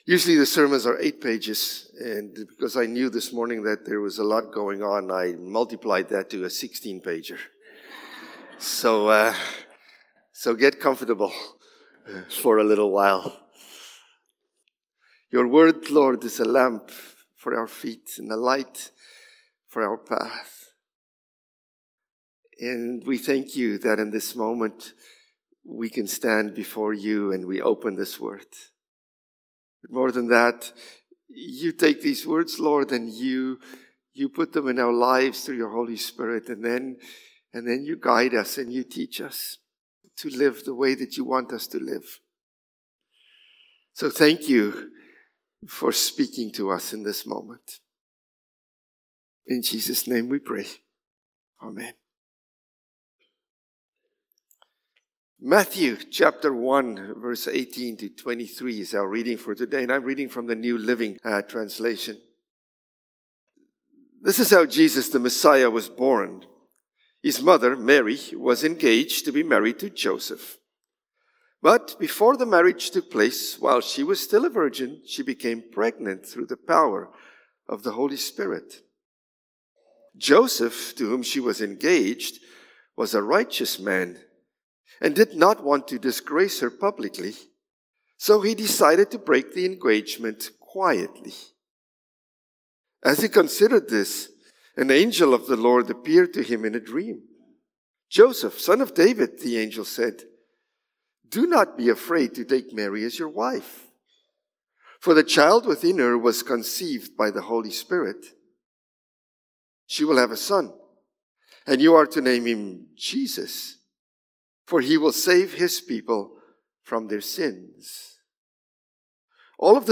December-15-Sermon.mp3